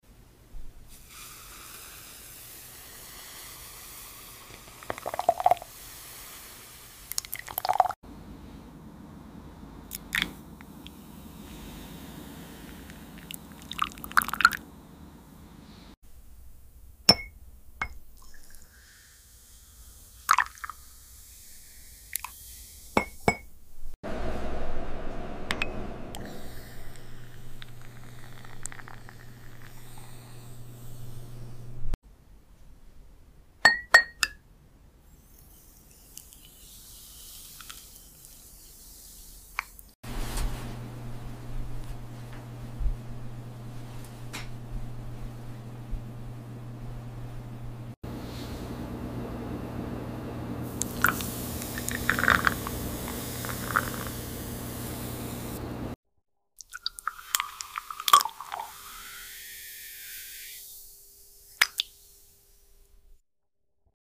☕ Coffee ASMR – Part sound effects free download
Grinding beans, pouring slowly, soft tapping on cups – welcome to Coffee ASMR 🧸✨ No talking. Just warm, rich sounds for your ears. Perfect for relaxing, focusing, or falling asleep.